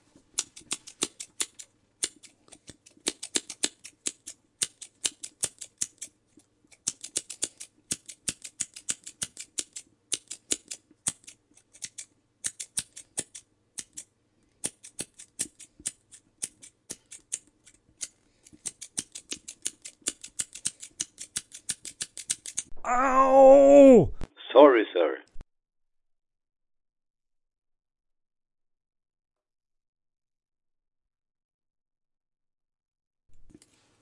毯子
描述：毯子的声音
标签： 覆盖下
声道立体声